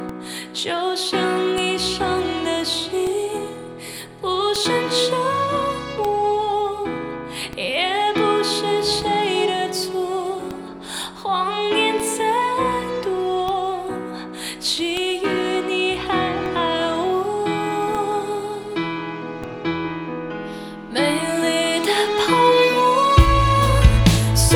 female.wav